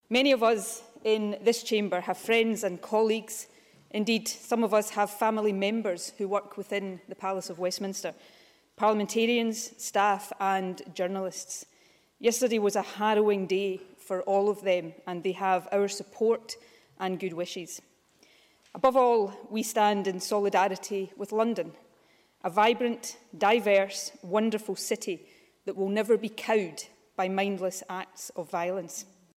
Nicola Sturgeon reflects on Westminster attack at First Minister’s Questions